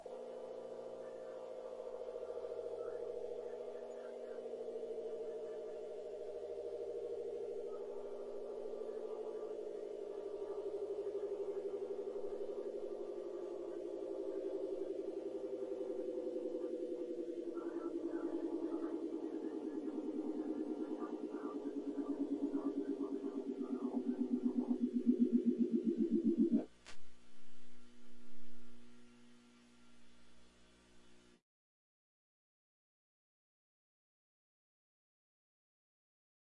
卷到卷的磁带倒退
描述：将卷轴重新卷绕到卷轴带上，记录到Bitwig中并使用低切割和压缩器进行处理
Tag: 模拟 卷轴 倒带 磁带 4轨 卷到卷 录音机 低保 复古